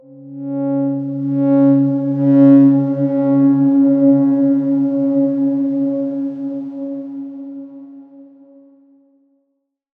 X_Darkswarm-C#3-pp.wav